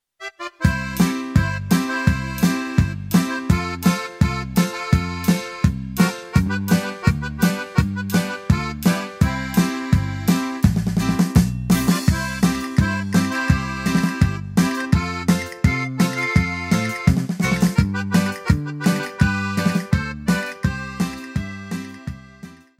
14-Corrido-1.mp3